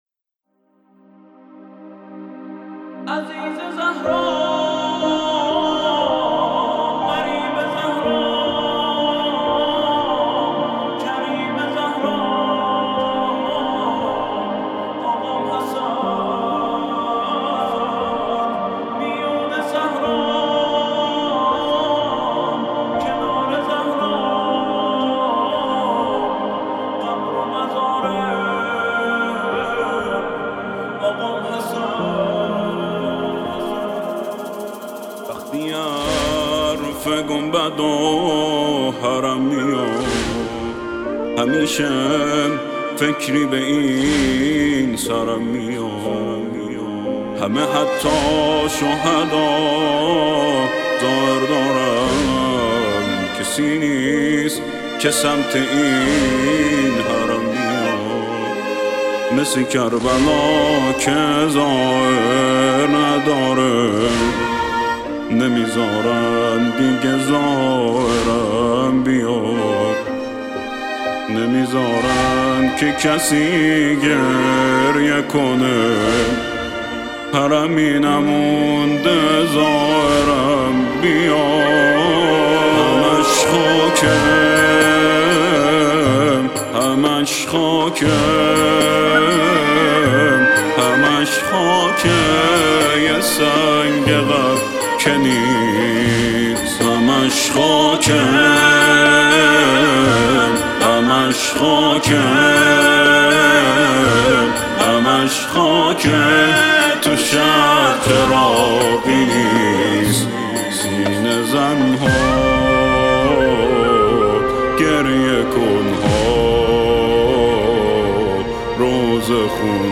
همش خاکه)موسیقی زیبا برای امام حسن